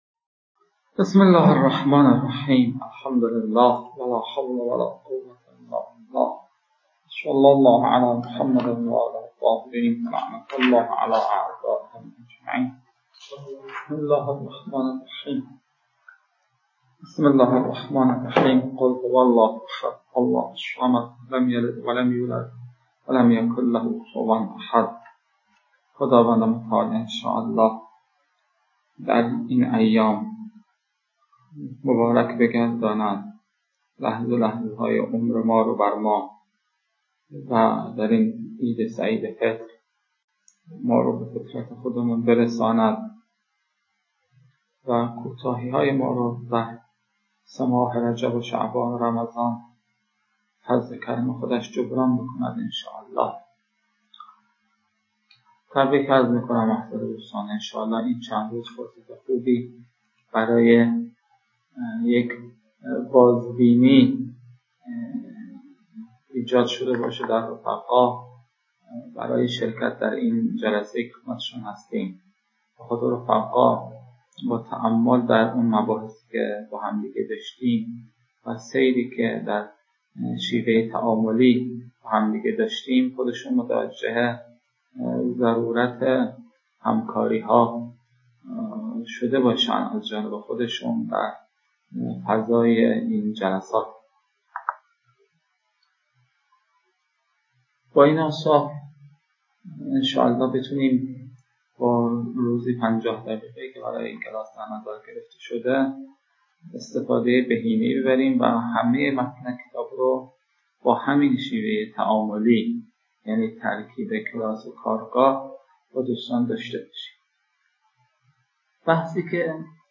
🔸 لازم به‌ذکر است که نتیجه این رویکرد، صرف پاره‌ای از بازه کلاس به رفت‌وبرگشت مبحث بین استاد و مخاطبان است که در کنار مجازی برگزارشدن کلاس، حوصله خاصی را در گوش دادن می‌طلبد. (البته فایل‌های صوتی بارها ویرایش شده‌اند تا کیفیت بهتر و مفیدتری داشته باشند.)